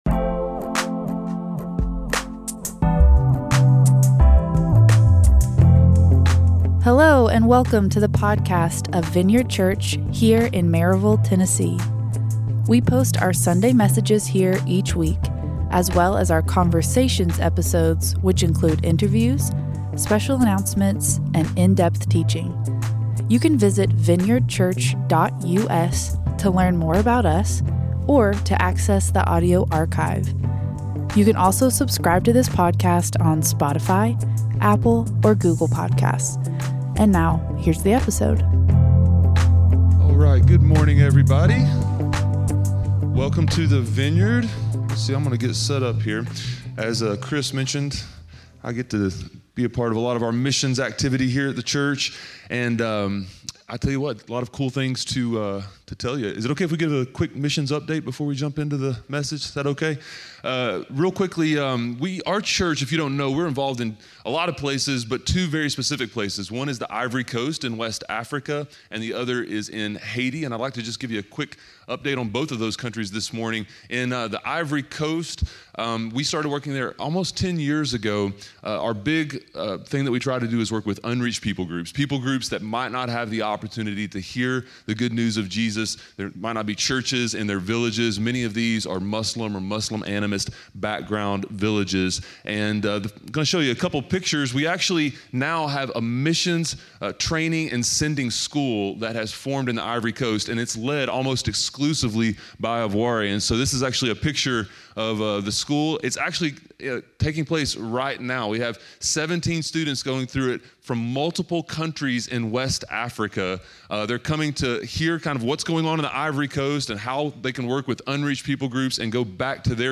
A message from the series "Win At All Costs."